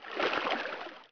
waterwalk1.wav